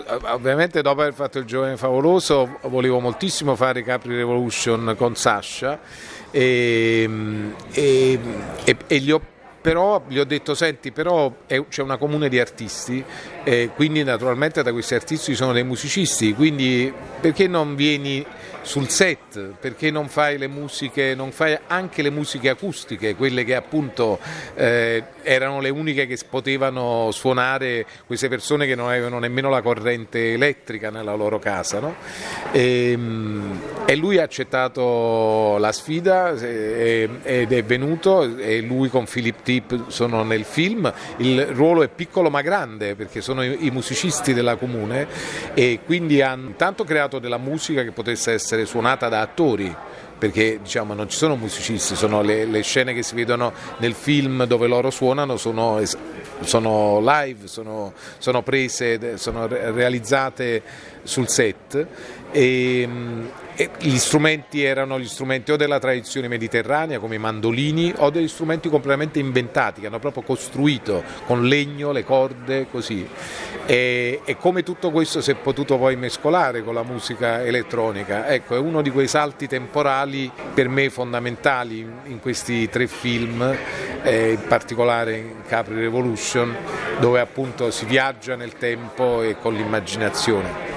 capri-revolution-mario-martone-parla-della-collaborazione-con-Sascha-Ring.mp3